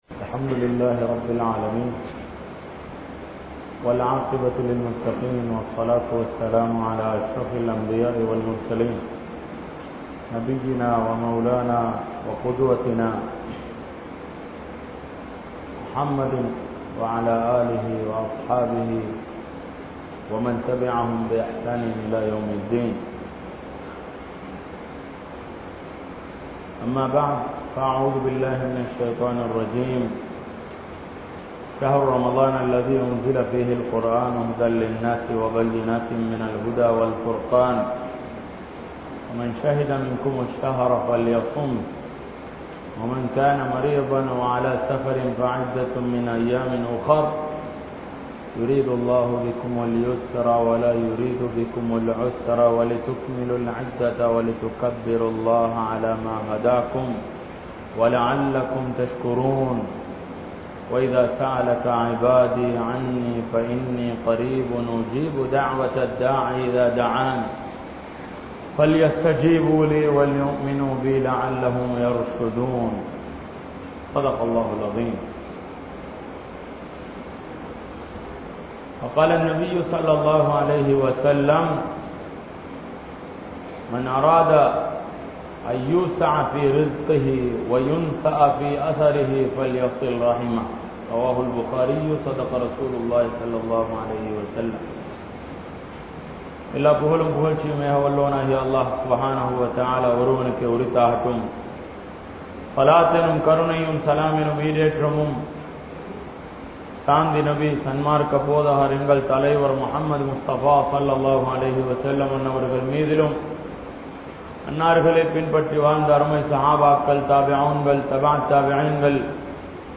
Ramalaanilum Thurpaakkiyasaalihal (ரமழானிலும் துர்ப்பாக்கியசாலிகள்) | Audio Bayans | All Ceylon Muslim Youth Community | Addalaichenai